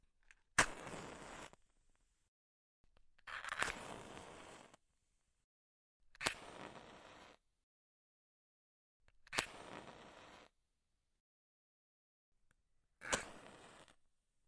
点燃一根火柴
描述： 打击比赛的一些变化。有一些慢的和快的。